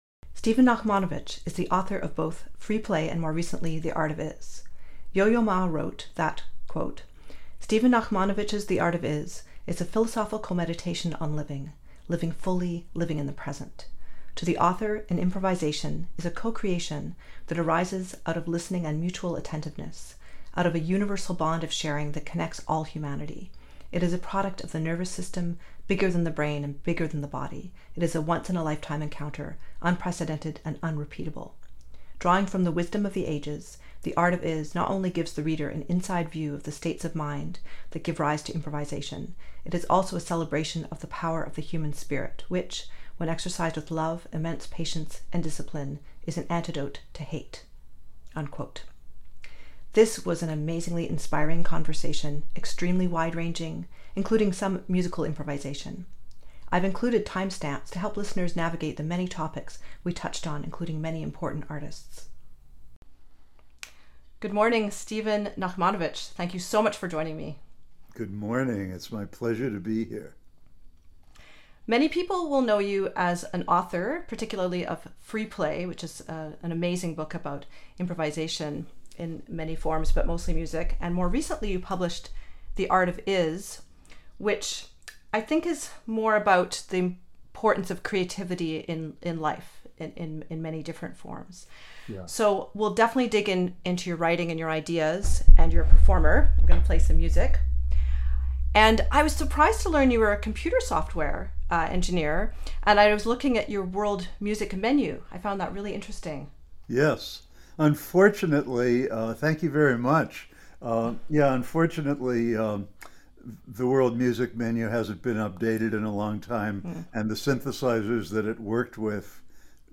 This was an amazingly inspiring conversation, extremely wide-ranging, including some musical improvisation.